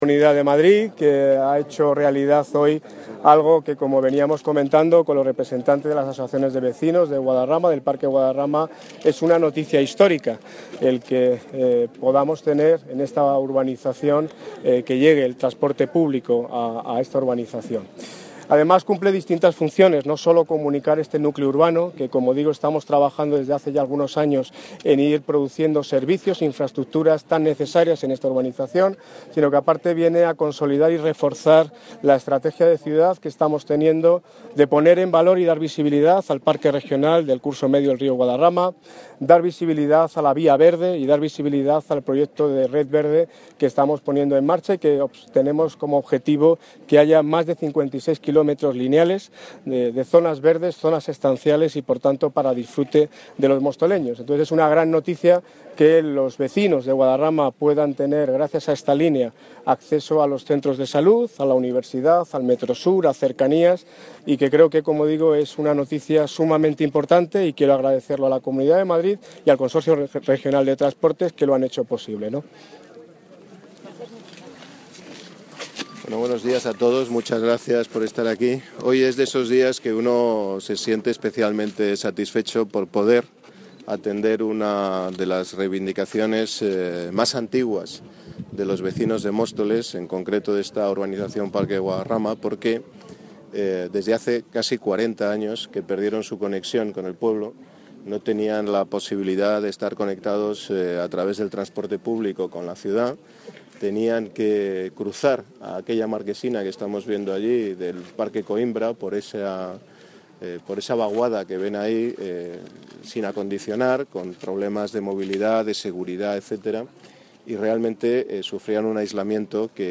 Alcaldía: González y Ortiz anuncian, en la presentación de la Línea 6, la construcción de una pasarela peatonal entre Parque Guadarrama y Parque Coimbra, una demanda histórica de los vecinos
Audio - Alcalde, Daniel Ortiz, y del Presidente de la Comunidad de Madrid, Ignacio González